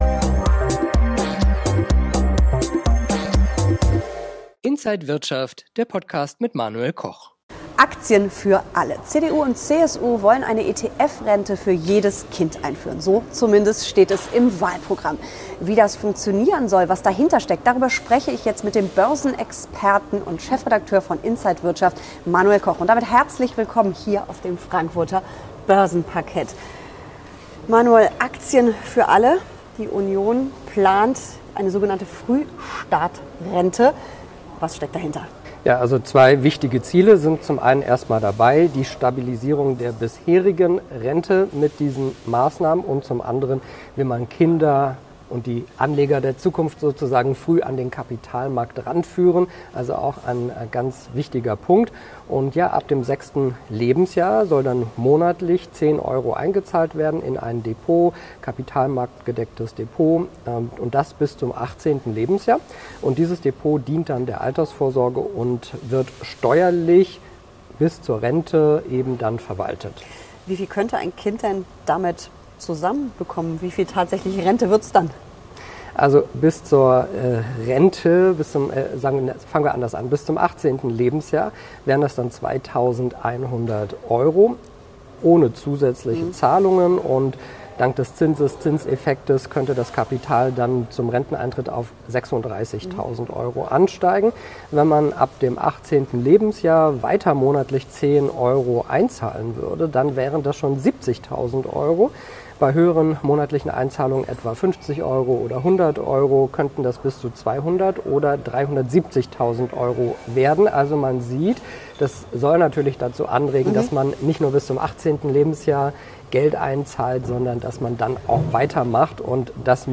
an der Frankfurter Börse